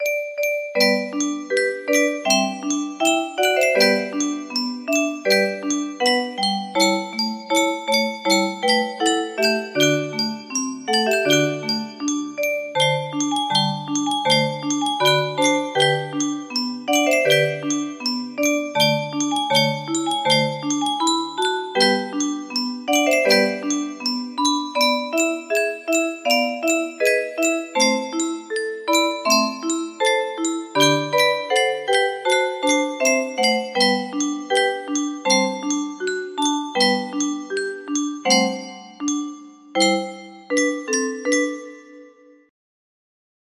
Antonio Zúñiga - Motete para el rosario music box melody
Motete de Antonio Zúñiga